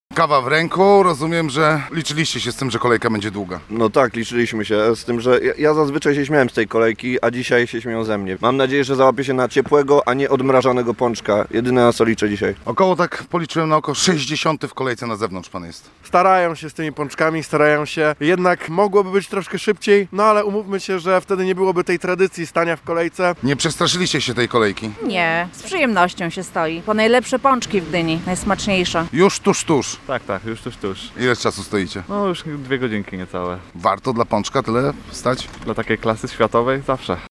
Z czekającymi na pączki rozmawiał nasz reporter.